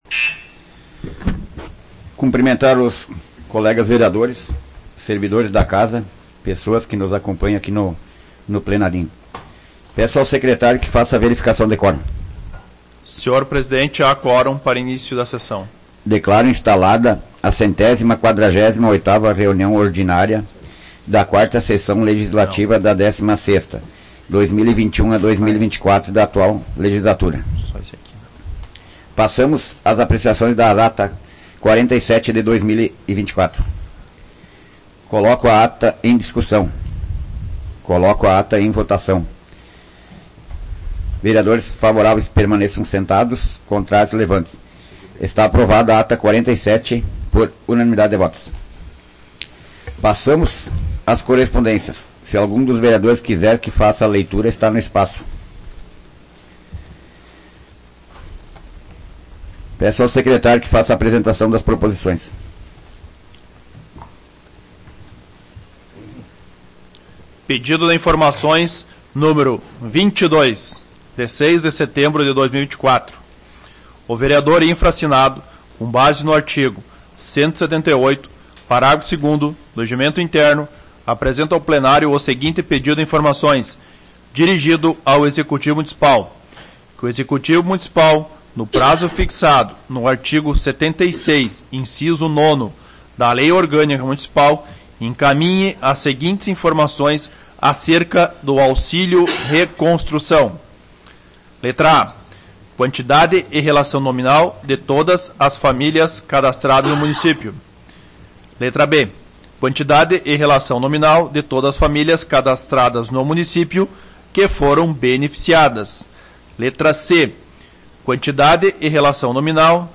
Áudio da 148ª Sessão Plenária Ordinária da 16ª Legislatura, de 09 de setembro de 2024